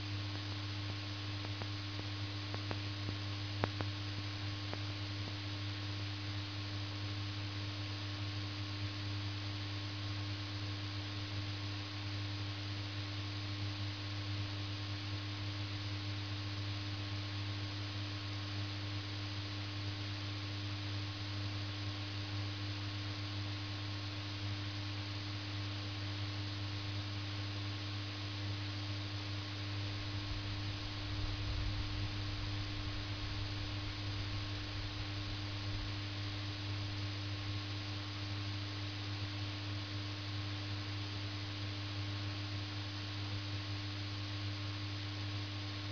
heathrd_9pt8km_NLOS.wav